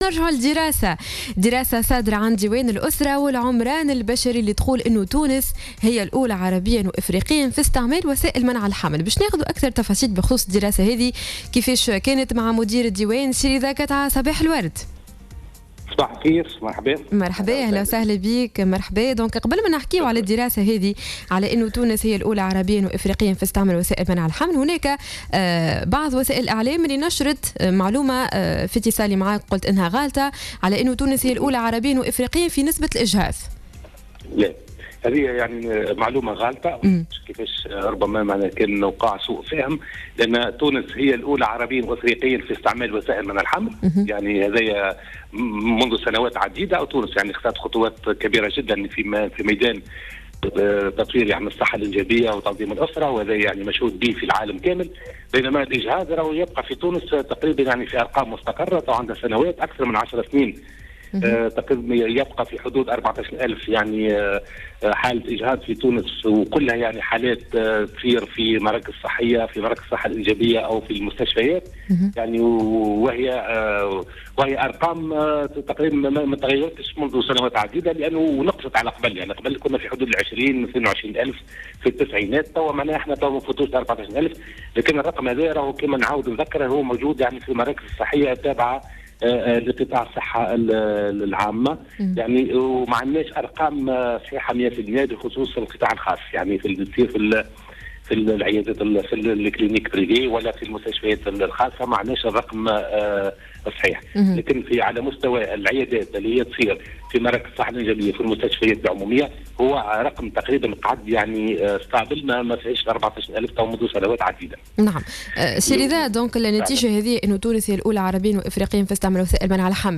أكد الرئيس المدير العام للديوان الوطني للأسرة والعمران البشري رضا قطعة في مداخلة على جوهرة "اف ام" صباح اليوم الثلاثاء 30 سبتمبر 2014 أن تونس تحتل المرتبة الأولى عربيا وافريقيا من حيث استعمال المرأة لوسائل منع الحمل حسب دراسة حديثة للديوان بهذا الخصوص.